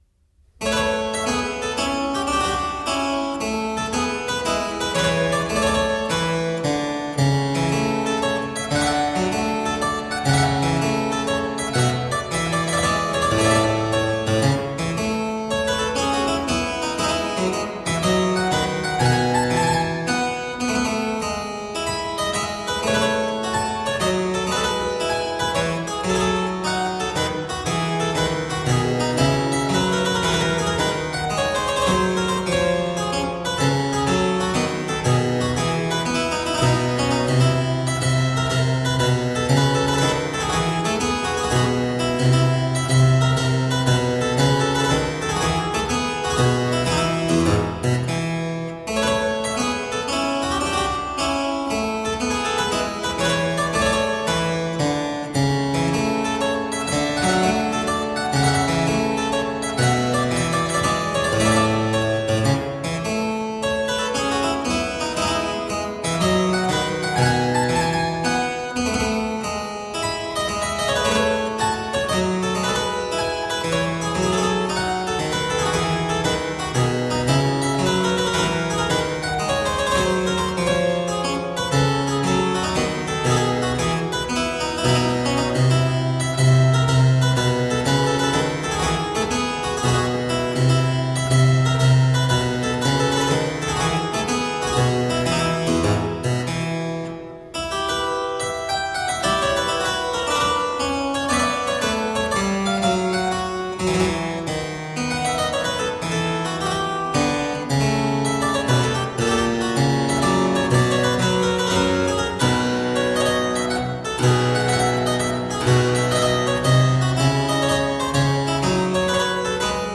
There are also harpsichord solos by Henry Purcell
Classical, Renaissance, Baroque